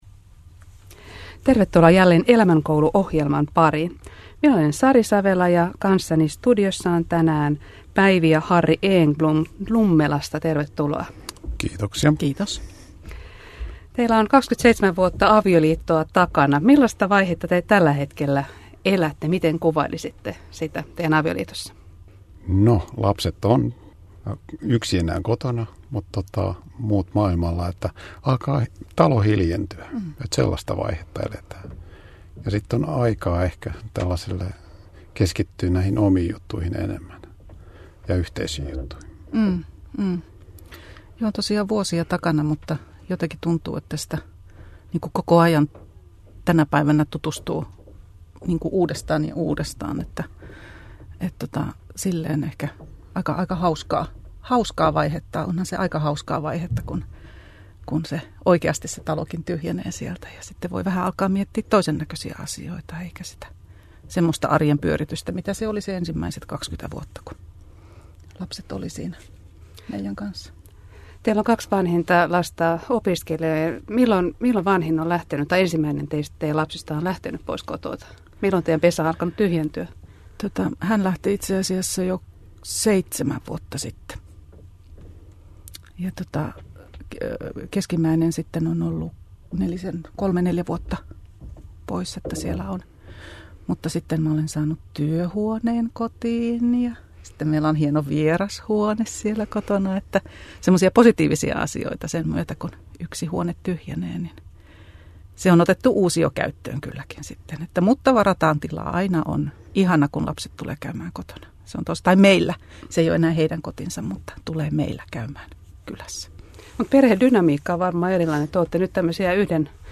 vieraina Elämänkoulu-ohjelmassa